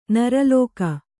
♪ naralōka